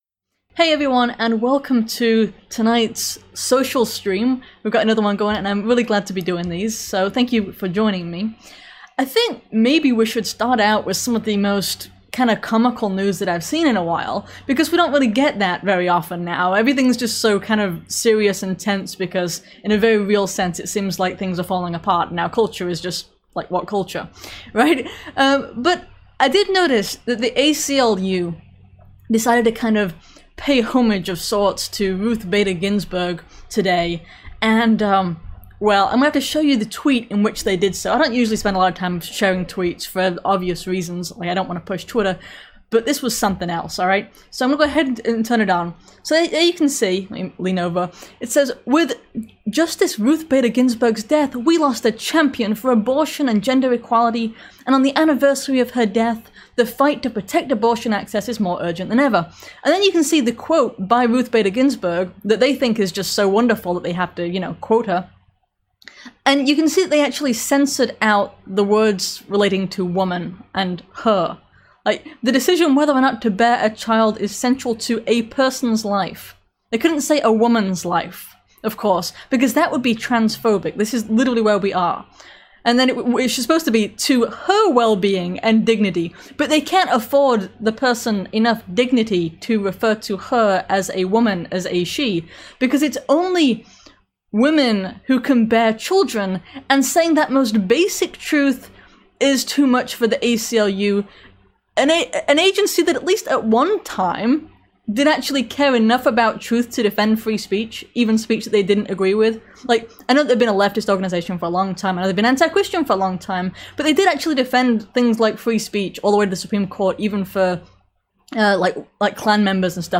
This live stream covered the hilarious instances of the left eating its own (from the ACLU to Black Lives Matter), the university that requires its students to deny basic truth, the fight against tyranny in Australia, and more.